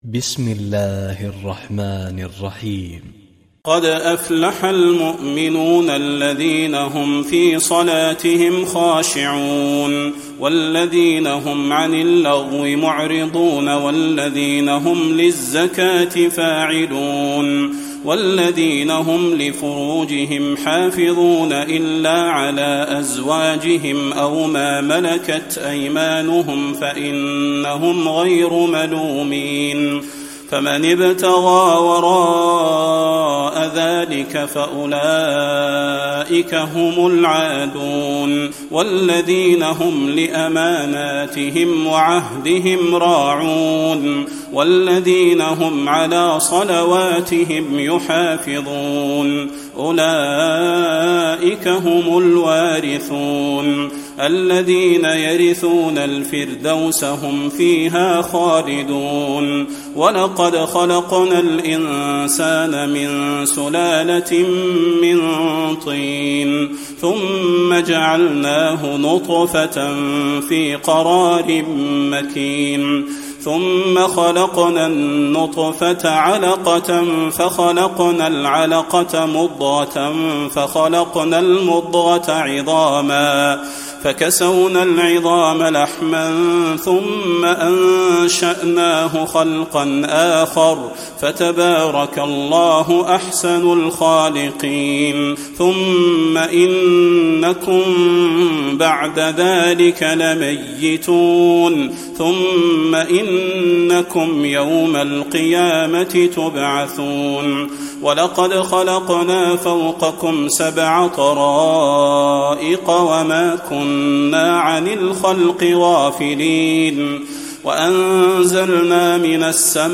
تراويح الليلة السابعة عشر رمضان 1435هـ سورتي المؤمنون و النور (1-20) Taraweeh 17 st night Ramadan 1435H from Surah Al-Muminoon and An-Noor > تراويح الحرم النبوي عام 1435 🕌 > التراويح - تلاوات الحرمين